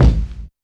kits/RZA/Kicks/WTC_kYk (75).wav at main